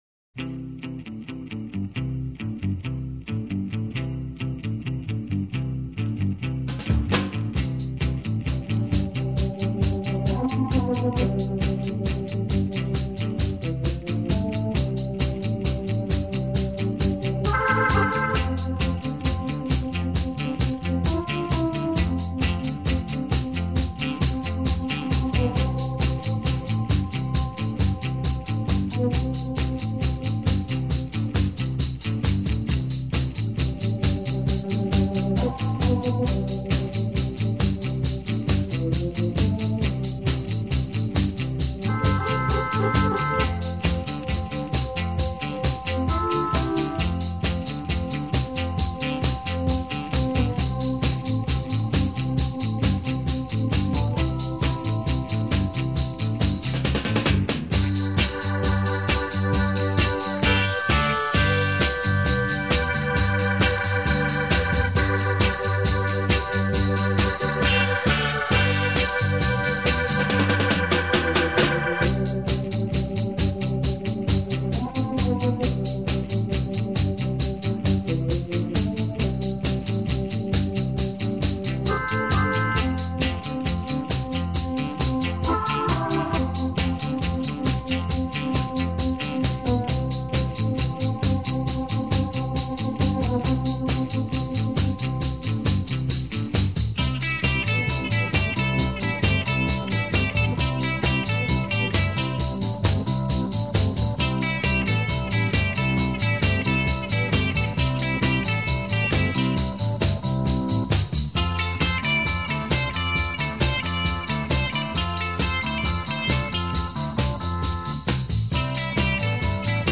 키보드 연주자가 그룹리더로 음악에서도 키보드 연주가 듣기 좋다.
역동적이고 영혼이 깃든 듯한 사운드가 특징이다.